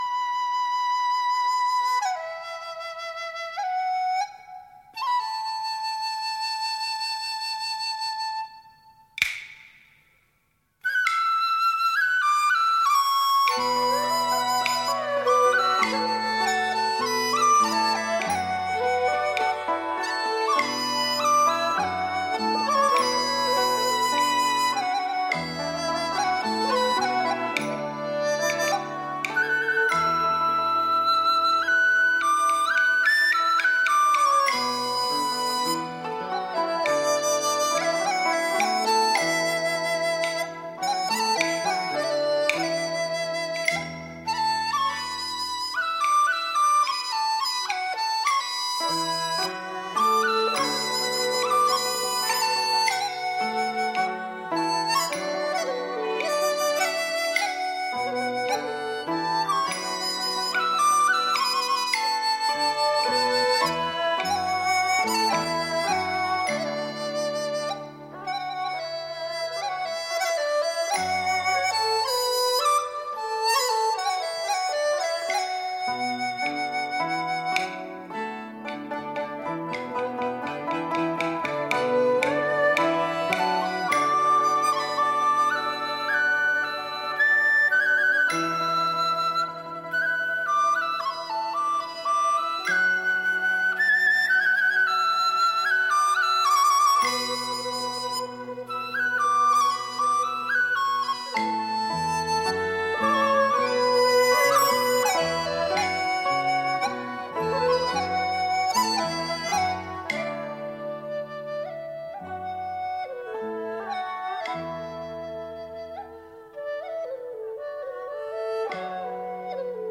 古曲